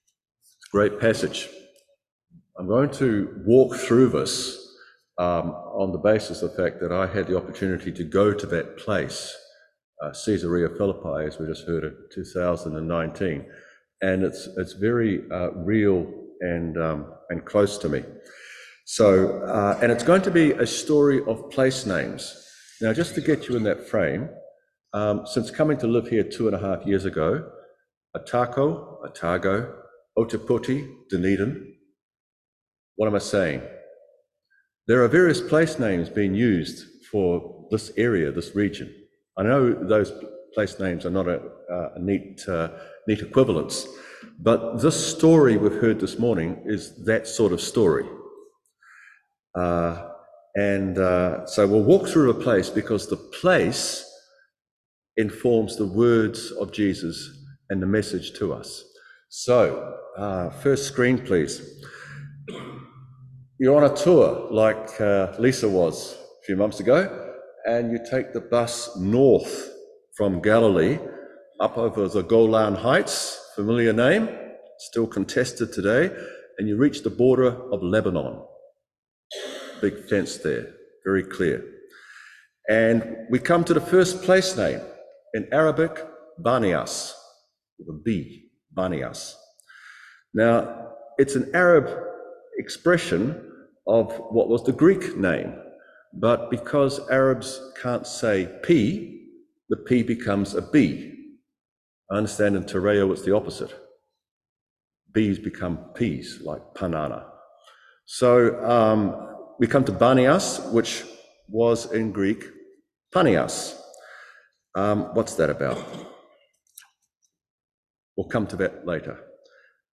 Matthew 16:13-20 Service Type: Morning Worship He said to them